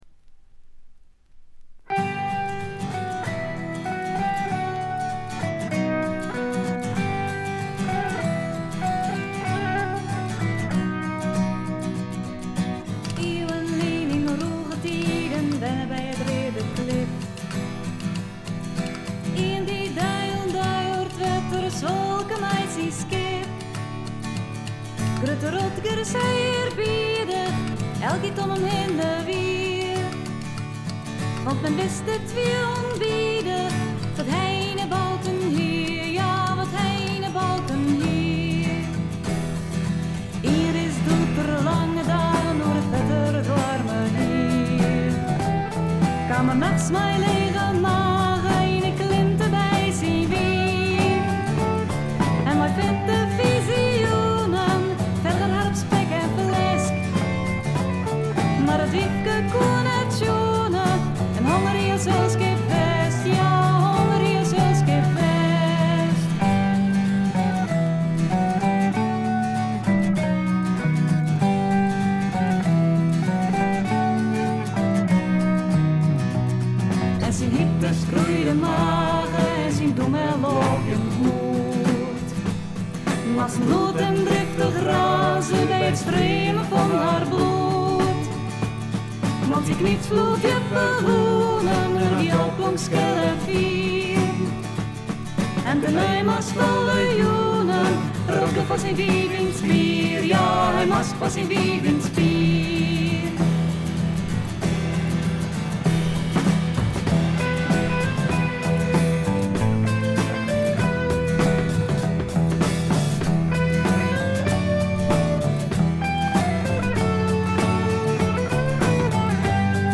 異国情緒が漂う女性ヴォーカルの美声に思わずくらくらしてしまうフィメールフォークの名作でもあります。
いわゆる妖精ヴォイスとは少し違って何というか少しざらついた感じがいいんですよ。
アコースティック楽器主体ながら多くの曲でドラムスも入り素晴らしいプログレッシヴ・フォークを展開しています。
試聴曲は現品からの取り込み音源です。